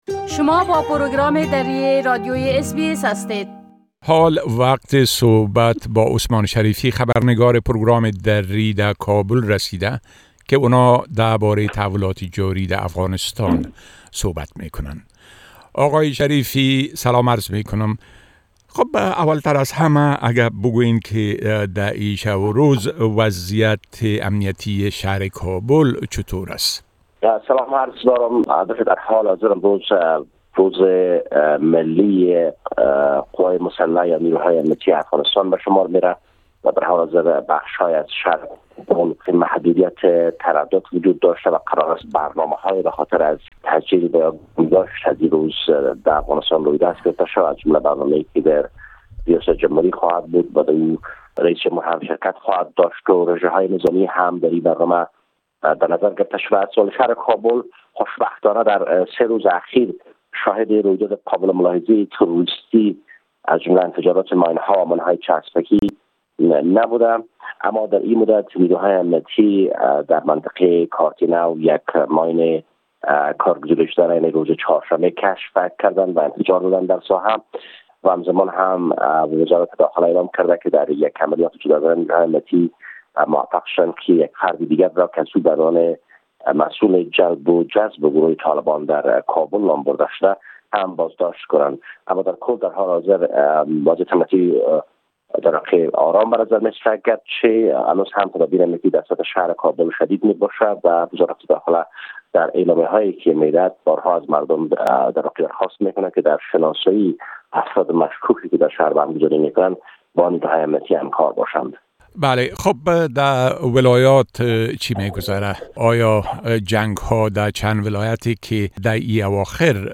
گزارش كامل خبرنگار ما در كابل، به شمول اوضاع امنيتى و تحولات مهم ديگر در افغانستان، را در اينجا شنيده ميتوانيد.